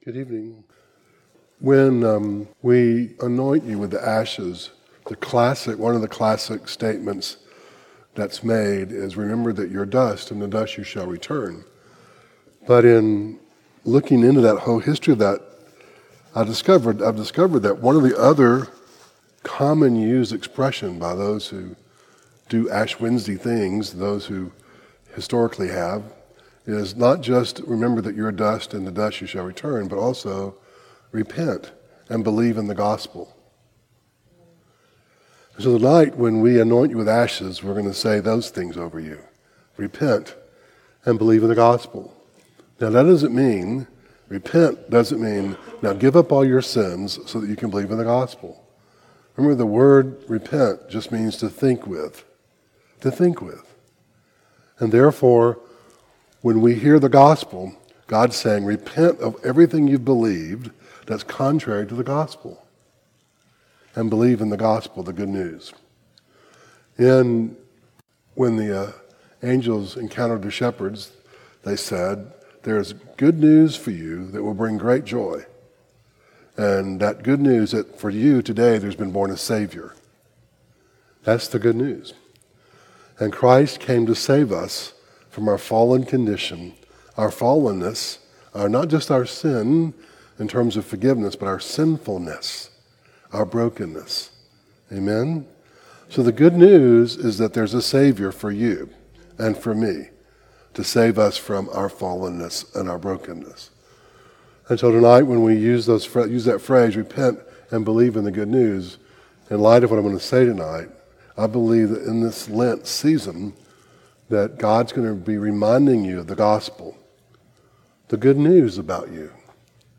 The Gospel In Condensed Form – Ash Wednesday Sermon
Service Type: Wednesday Night